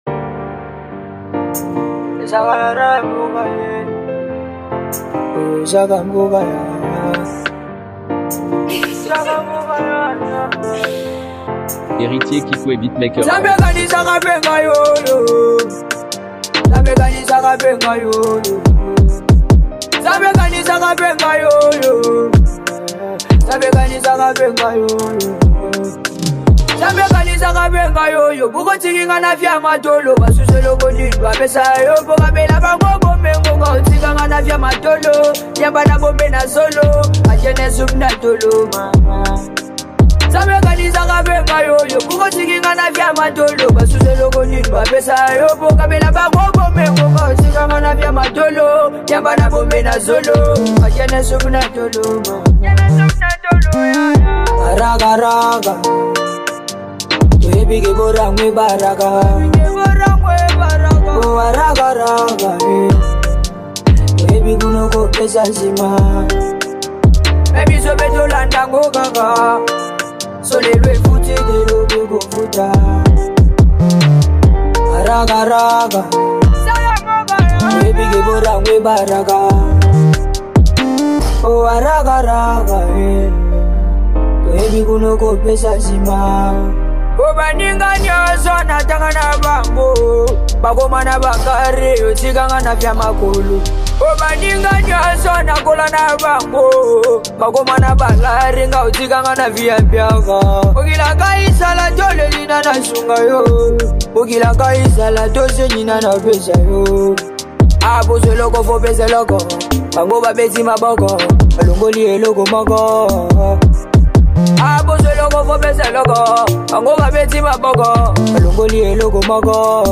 | RnB